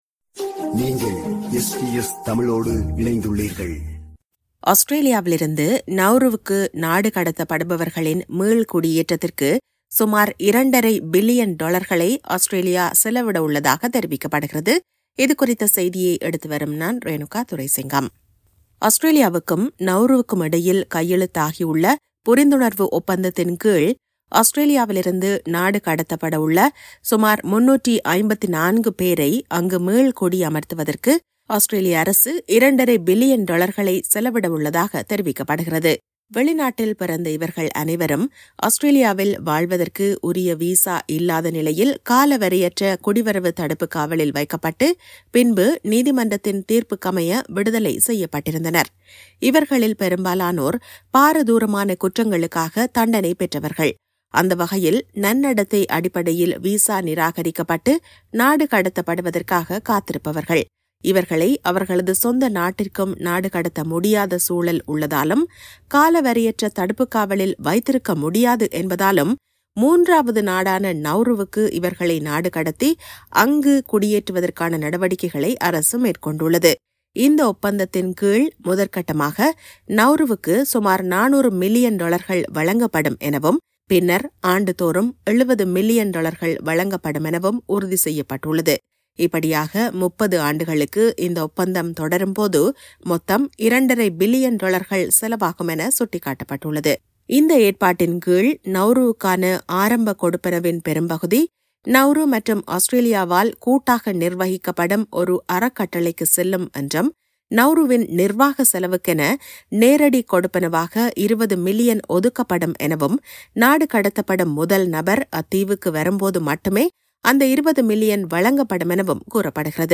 ஆஸ்திரேலியாவிலிருந்து நவுருவுக்கு நாடுகடத்தப்படுபவர்களின் மீள்குடியேற்றத்திற்கு சுமார் இரண்டரை பில்லியன் டொலர்களை ஆஸ்திரேலியா செலவிடவுள்ளதாக தெரிவிக்கப்படுகிறது. இதுகுறித்த செய்தியை எடுத்துவருகிறார்